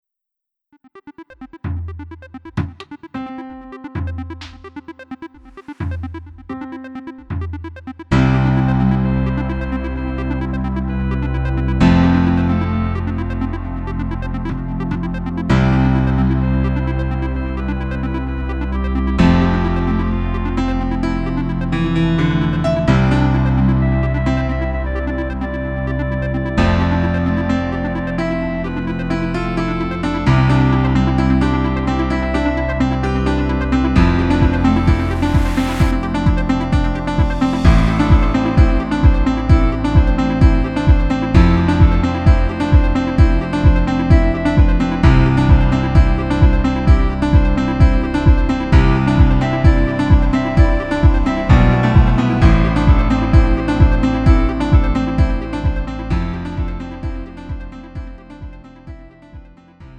음정 원키 3:37
장르 구분 Lite MR